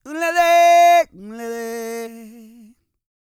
E-CROON 207.wav